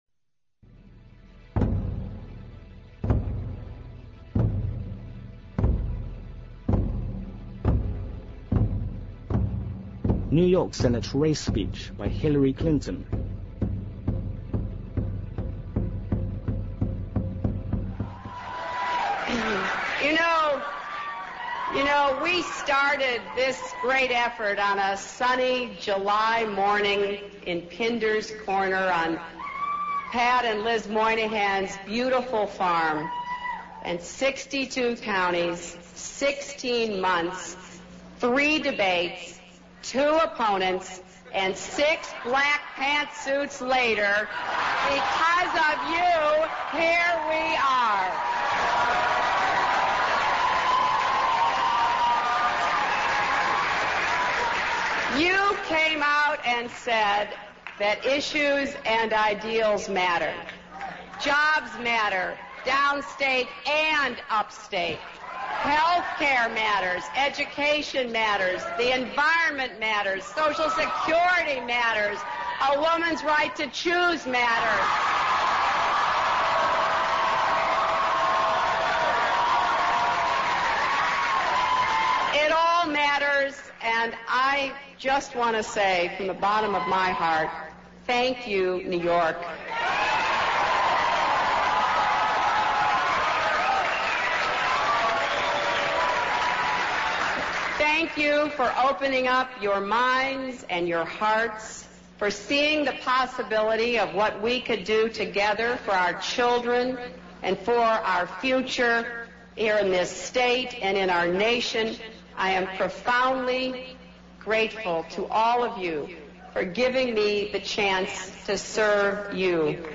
NEW YORK SENATE RACE SPEECH 听力文件下载—在线英语听力室